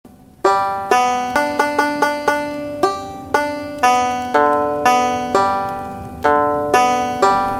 нужно выучить положение нот для воспроизведение на банджо.